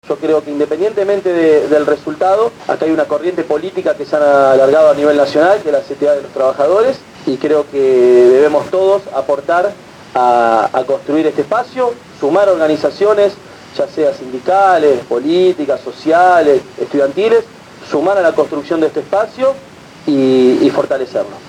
El último lunes se realizó en las instalaciones de Radio Gráfica la charla-debate: «Los Trabajadores y el Proyecto Nacional«, todo ésto de cara a las elecciones internas de la Central de Trabajadores de la Argentina el próximo 23 de Septiembre.